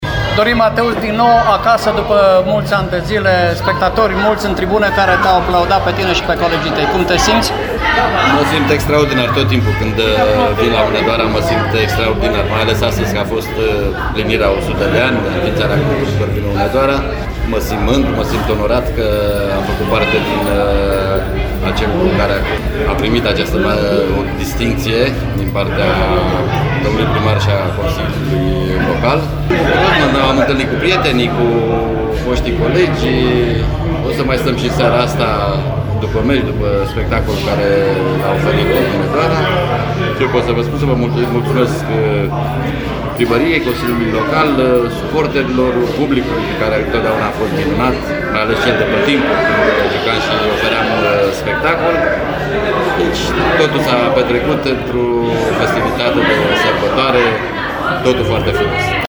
În fișierele AUDIO găsiți interviurile cu Remus Vlad, Dorin Mateuț și Mircea Rednic: